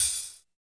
84 HAT 2  -L.wav